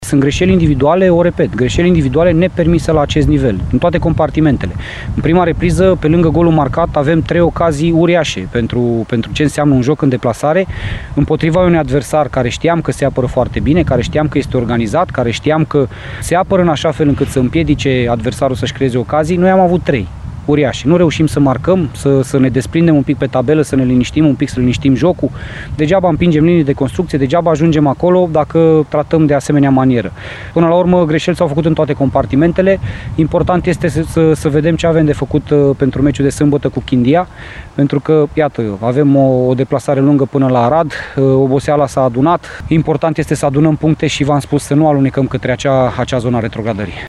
La final, antrenorul UTA-ei, Laszlo Balint, le-a reproșat jucătorilor săi greșelile personale care au dus atât la ratările uriașe, cât și la golurile piteștenilor: